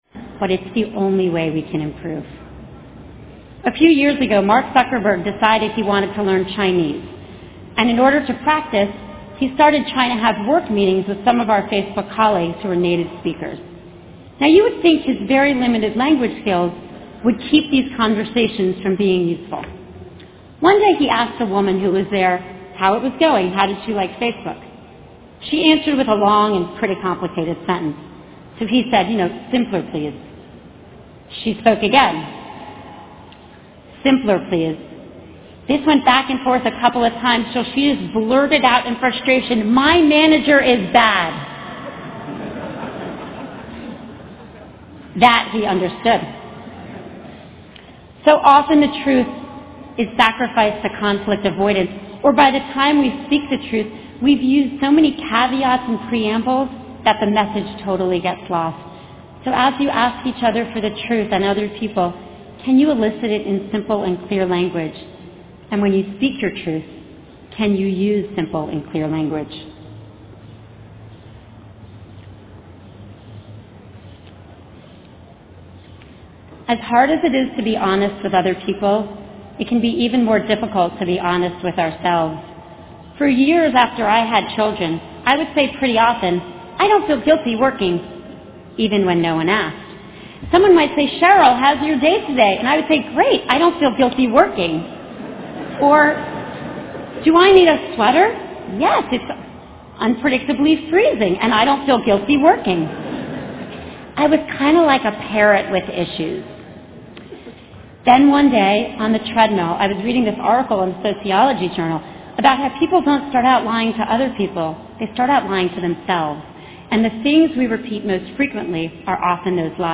公众人物毕业演讲第3期:雪莉桑德伯格哈佛大学2014毕业典礼演讲(3) 听力文件下载—在线英语听力室